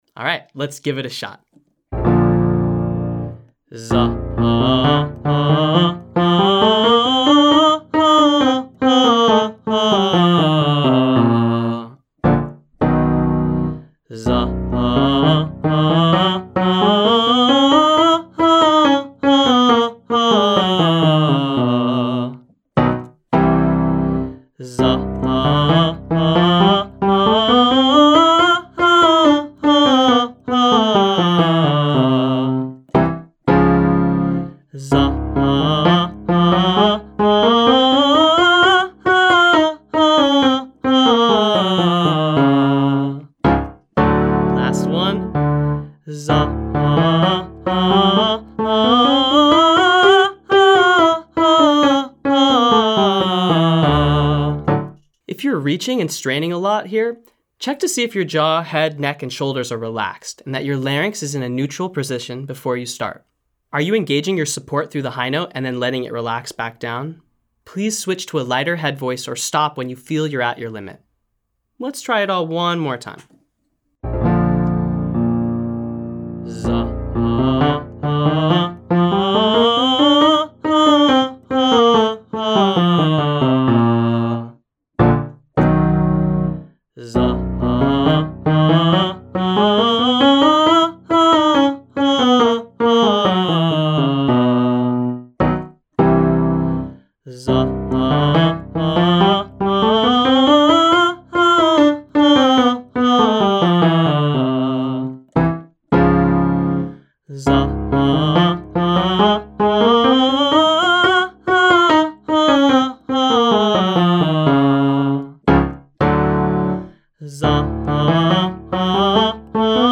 Mix-belt Range Extension - Online Singing Lesson
From: Daily Range Extension Practice For Low Voices